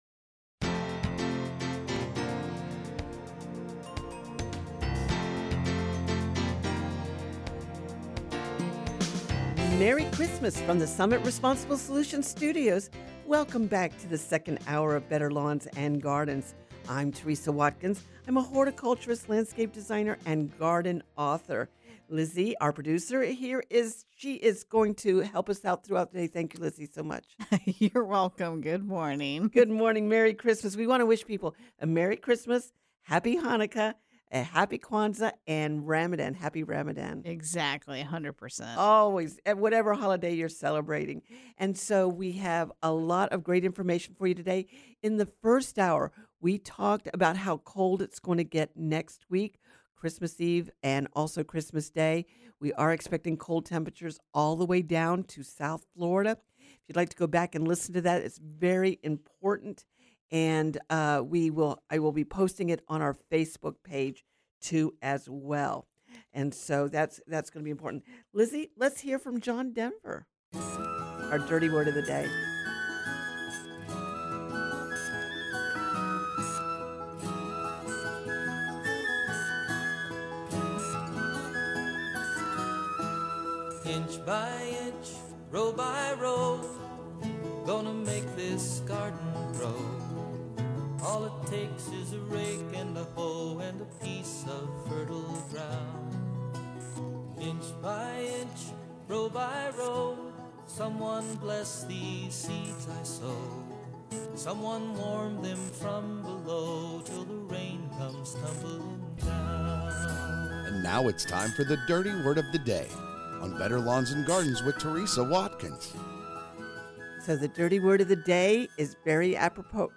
Coming to you from Summit Responsible Solutions Studios
Dirty Word of the Day is Polar Vortex. Garden questions and texts include holiday greetings from listeners, mulching bananas, will milkweed survive a freeze, insulating plumeria, and more. https